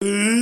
「あ～」という声。
あー.mp3